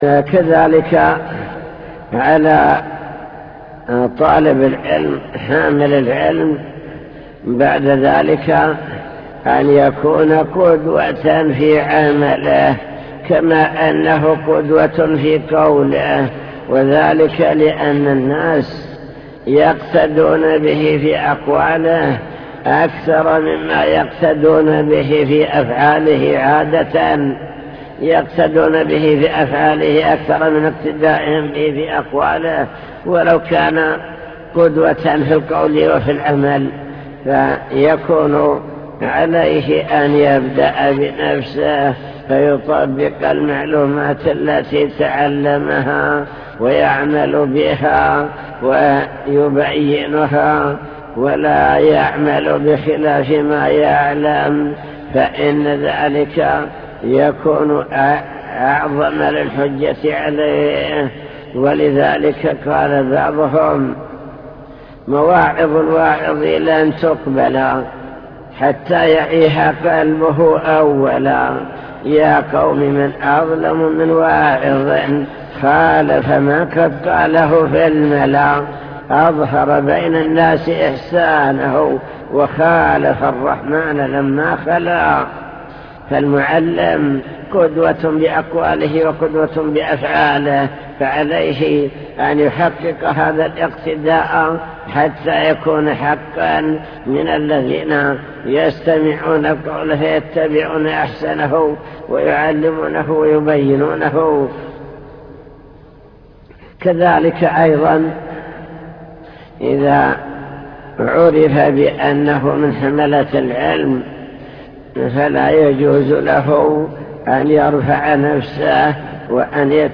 المكتبة الصوتية  تسجيلات - لقاءات  لقاء مفتوح مع الشيخ